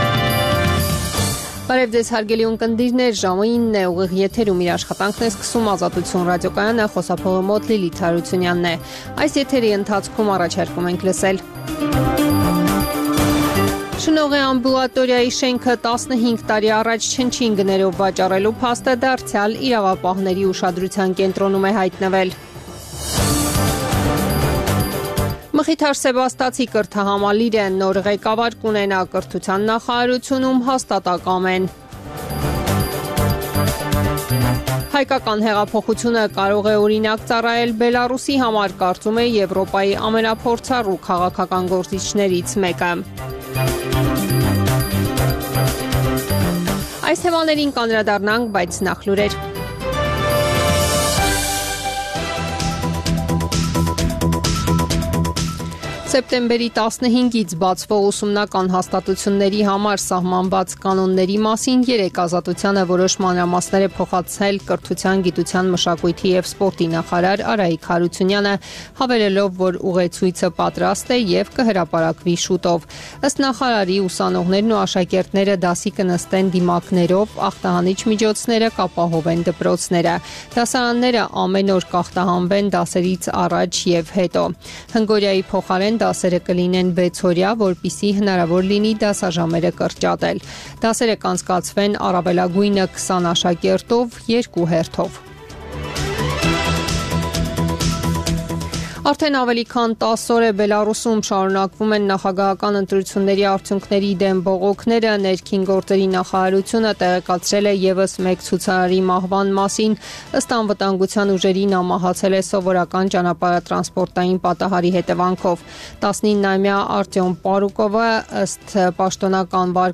Տեղական եւ միջազգային լուրեր, ռեպորտաժներ, հարցազրույցներ, տեղեկատվություն օրվա սպասվող իրադարձությունների մասին, մամուլի համառոտ տեսություն: